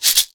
Shaken Maracas 03.wav